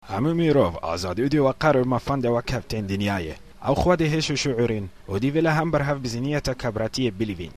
Here’s a recording in a mystery language.
It sounds CV-syllabic and I hear something like [wa] a couple of times.